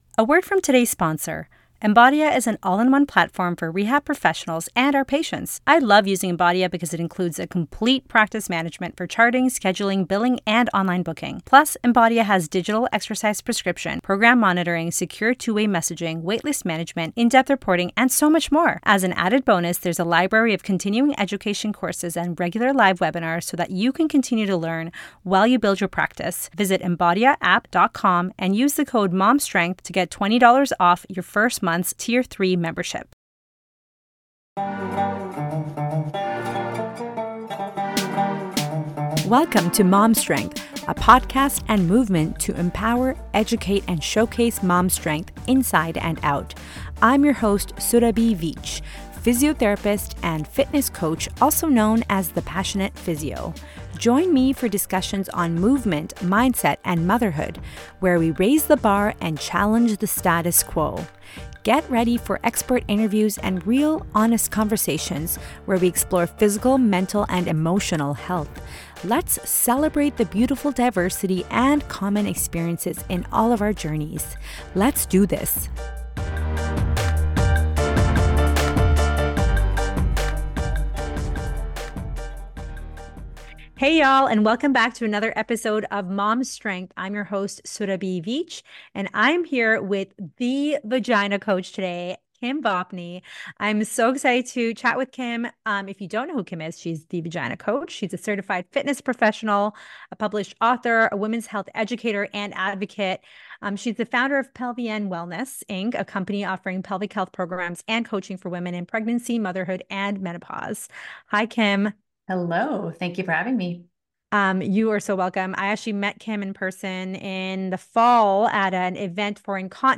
Join us for a candid conversation about breaking taboos, empowering women, and redefining pelvic health across all life stages.